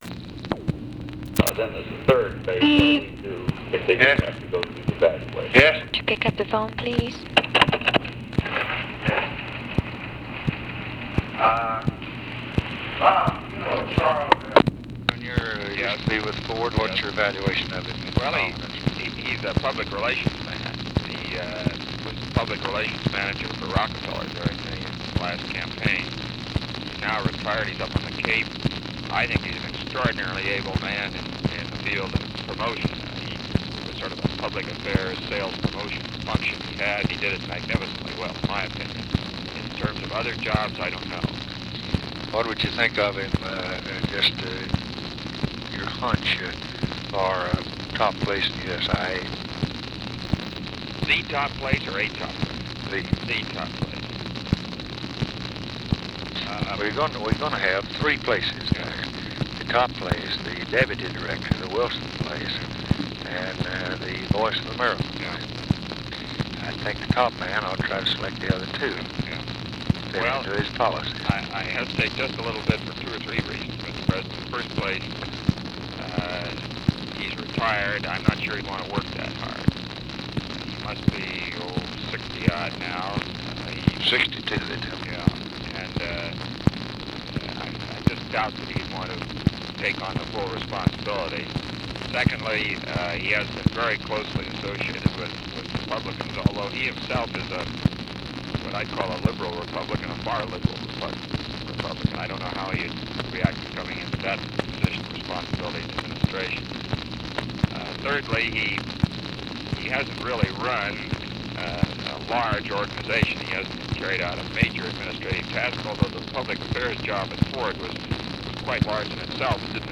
Conversation with ROBERT MCNAMARA and OFFICE CONVERSATION, July 8, 1965